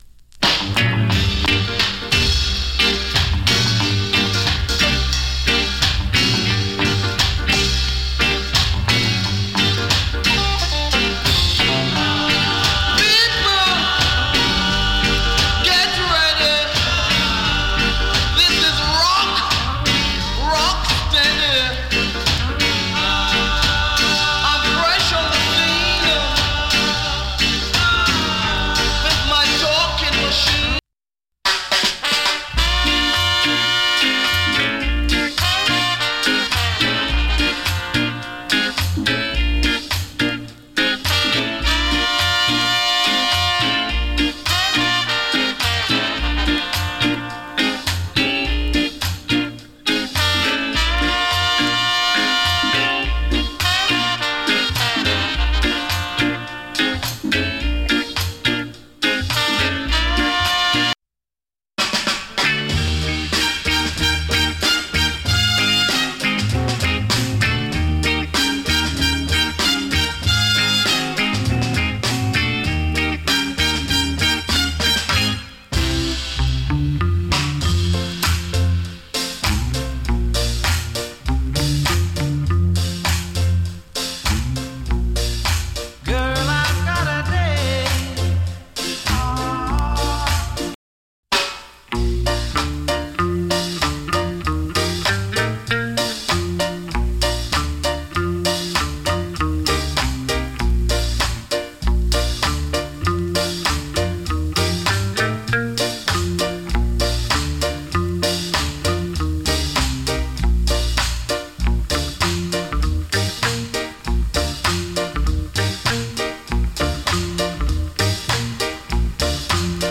チリ、パチノイズわずかに有り。
KILLER ROCK STEADY から CALYPSO までの好内容 !